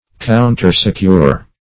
countersecure - definition of countersecure - synonyms, pronunciation, spelling from Free Dictionary